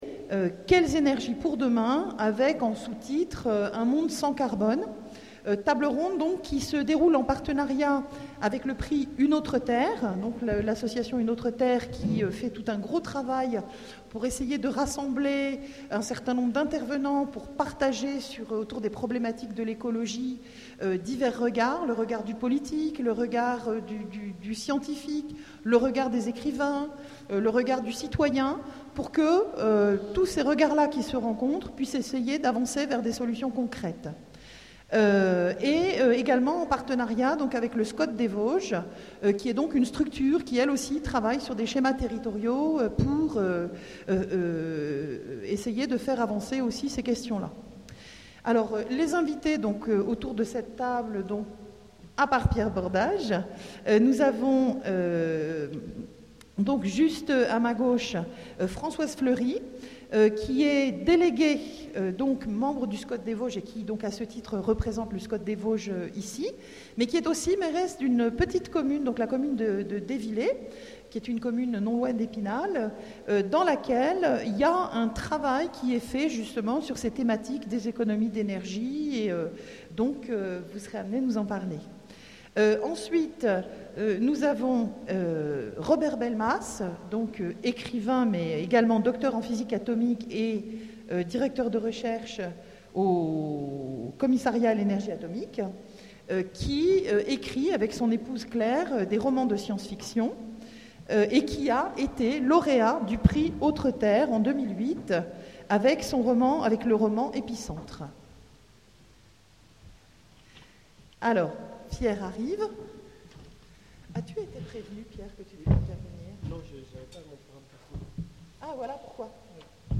Imaginales 2014 : Conférence Quelle énergie pour demain?